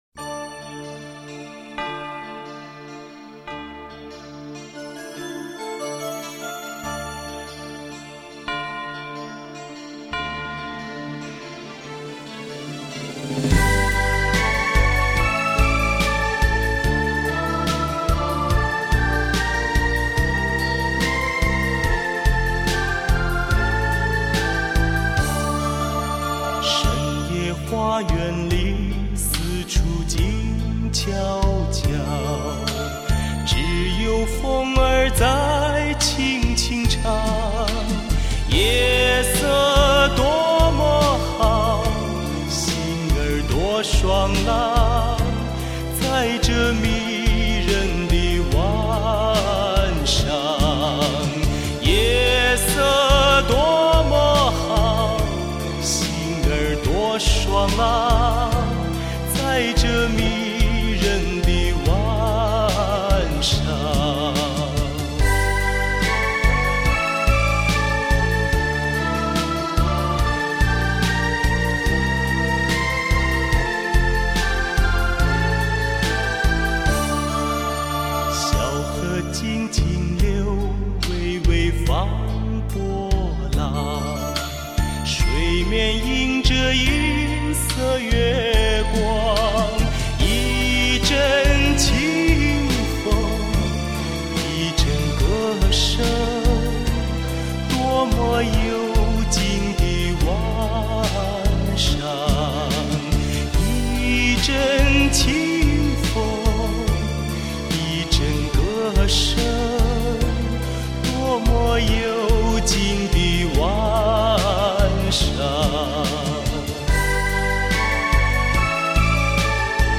录音上佳